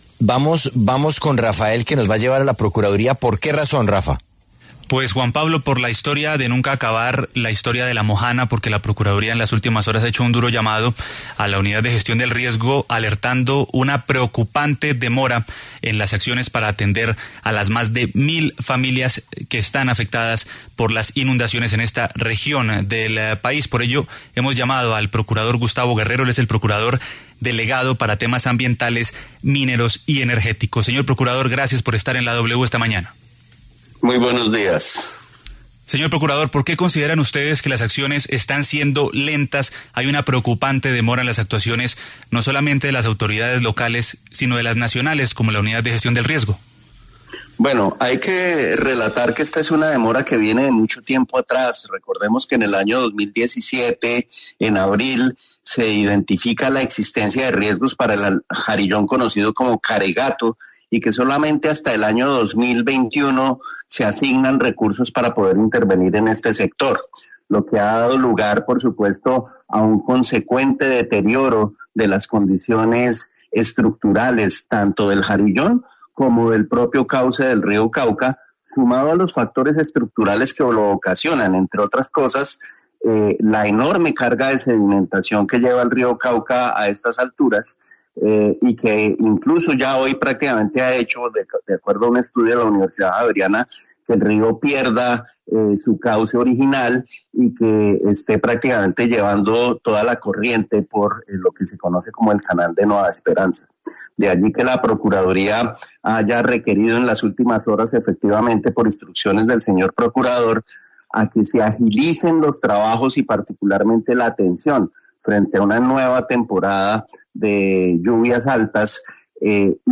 En entrevista con La W, el procurador delegado para temas Ambientales, Mineros y Energéticos, Gustavo Guerrero, se refirió a la alerta del ministerio público sobre la situación en La Mojana y la “preocupante demora” en las acciones de los gobiernos locales y nacionales para contener las inundaciones.